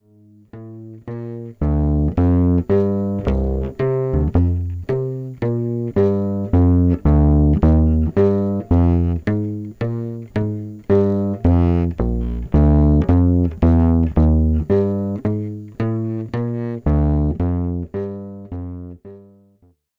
当然ながらこのピックアップは1,2弦の音しか拾わないので、低音側は「The Realist」を使用します。 で、それぞれをミックスした音をPCMレコーダーで録音してみました
これも良い感じなのですが、ノイズがあり荒っぽいのと、1弦と2弦の音量バランスがちょっと悪いです。
friskpickup_realist.mp3